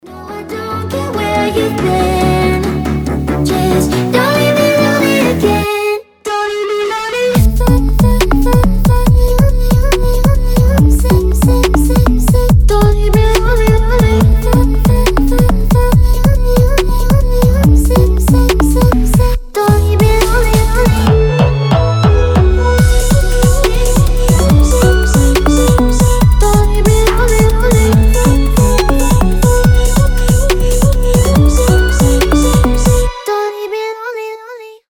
• Качество: 320, Stereo
милые
Electronic
красивый женский голос